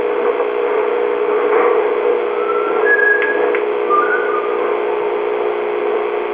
Some E.V.P. captured at St. Mary's Hospital:
EVP_Whistler_in_psych_room:   When a recorder was put into the psychiatric room, this whistle EVP was captured.
EVP_Whistler_in_psych_room.wav